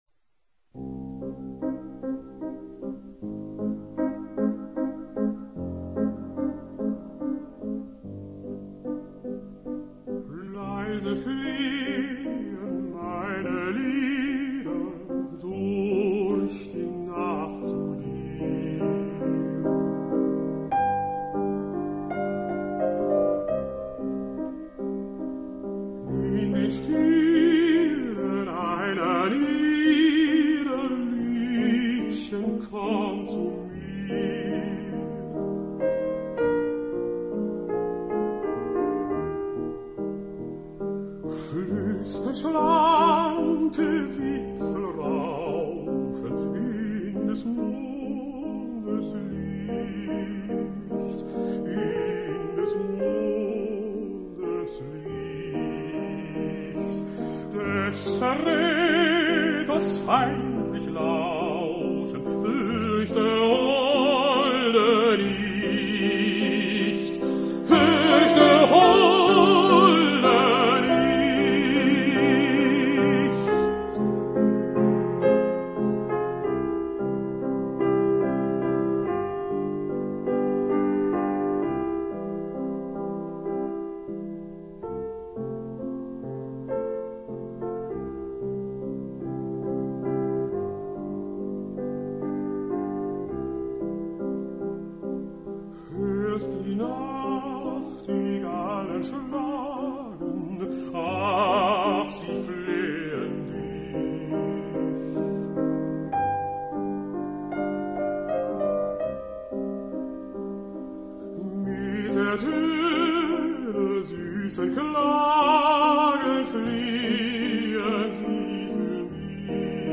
Voix d'hommes - Coups de coeur
Baryton - Dietrich Fischer-Dieskau
0416_Schwanengesang_Swann_song_Franz_Schubert_Baryton_Dietrich_Fischer-Dieskau.mp3